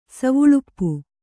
♪ savuḷuppu